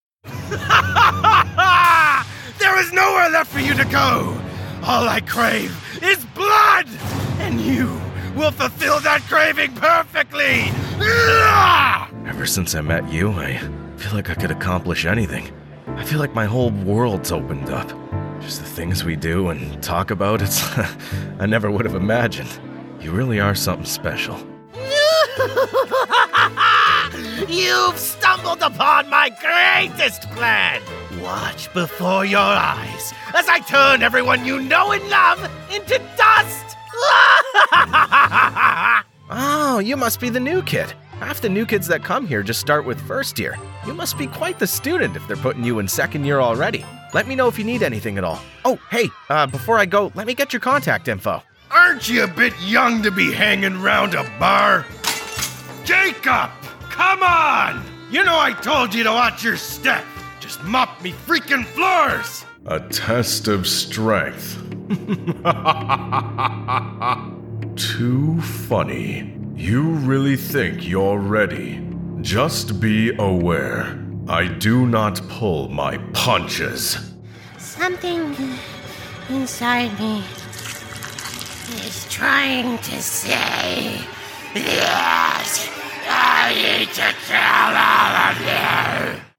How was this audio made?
All recordings are done in my professional sound-treated vocal booth.